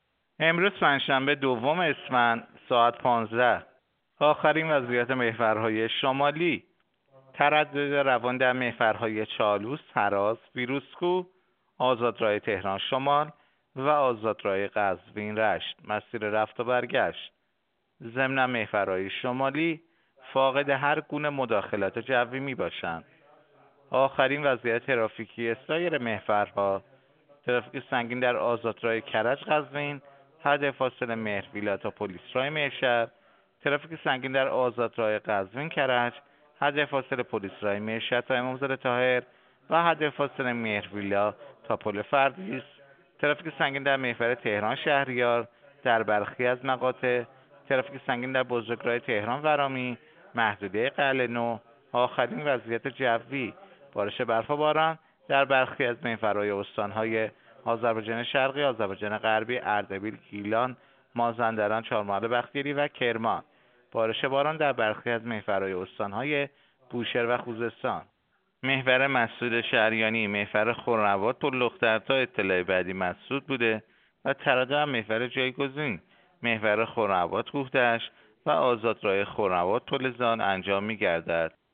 گزارش رادیو اینترنتی از آخرین وضعیت ترافیکی جاده‌ها ساعت ۱۵ دوم اسفند؛